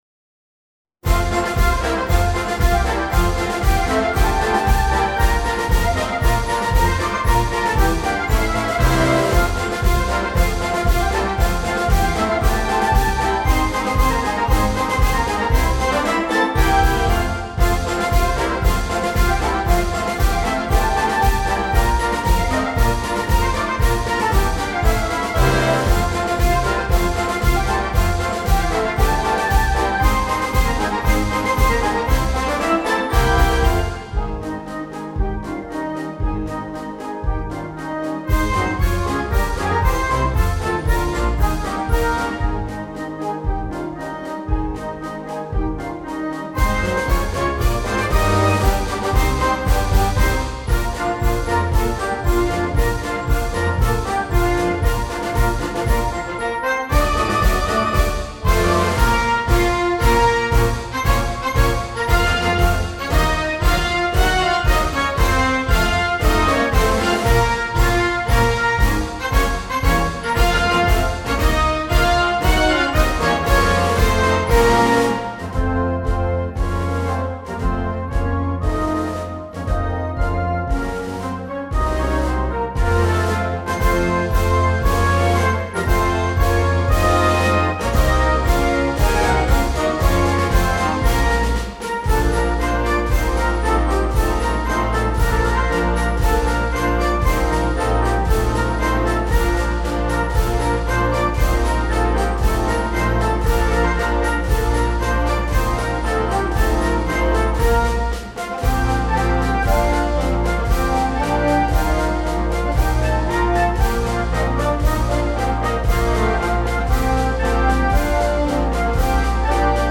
Gattung: Marsch
Besetzung: Blasorchester
Medley-Marsch